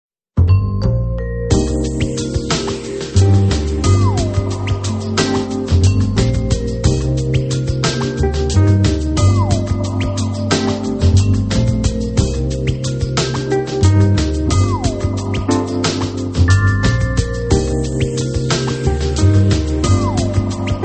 The Whistle